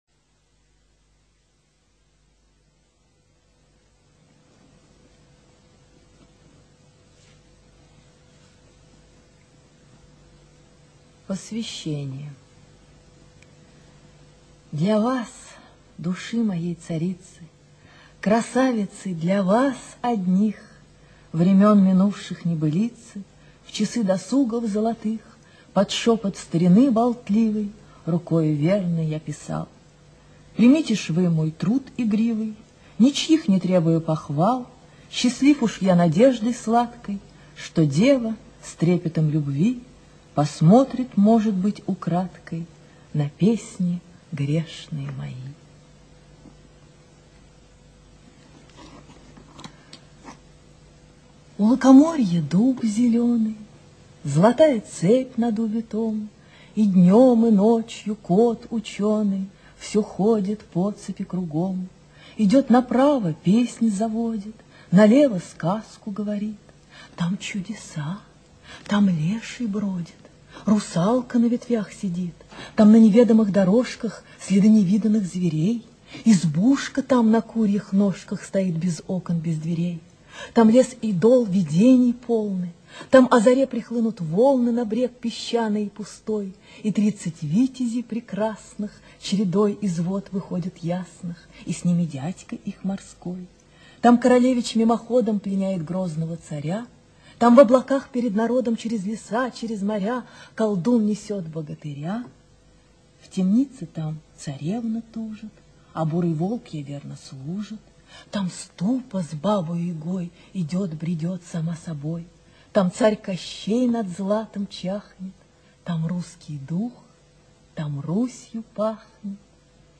ЧитаетДемидова А.
Пушкин А - Руслан и Людмила (Демидова А.)(preview).mp3